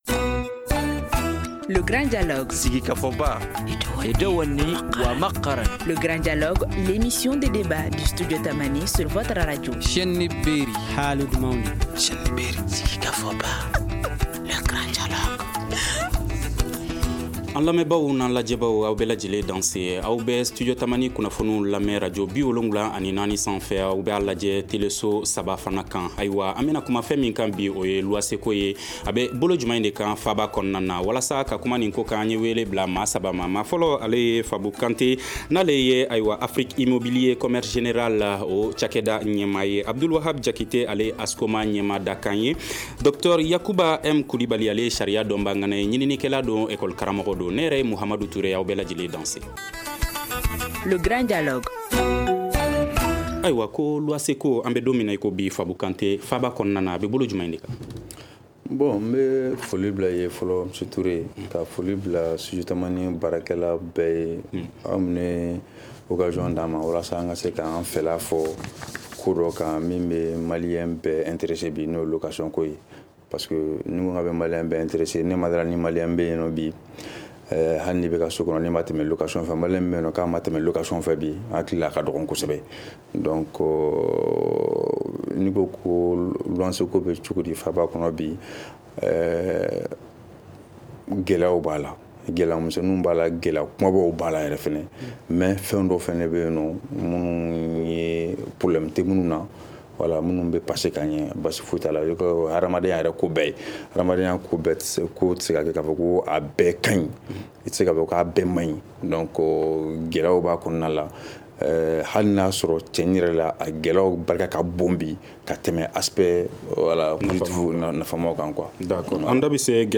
Comment relever les défis de ce domaine ?Le Grand Dialogue de Studio Tamani pose le débat.